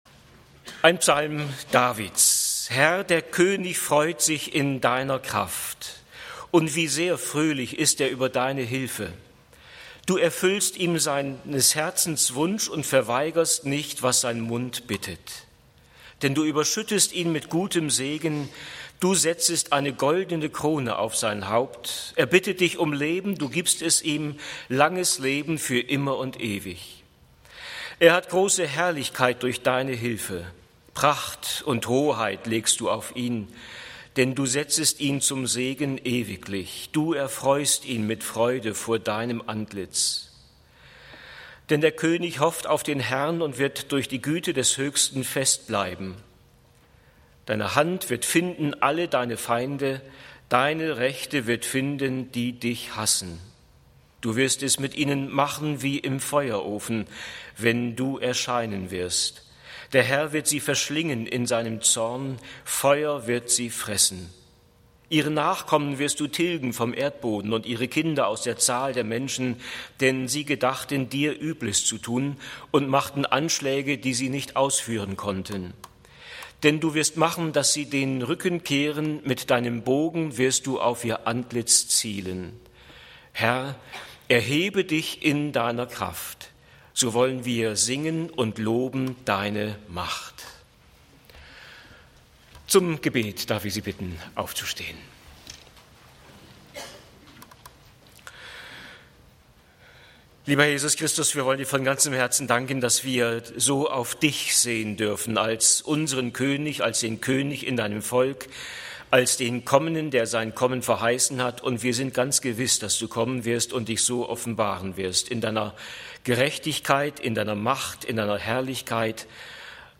Kö. 5,1-19) - Gottesdienst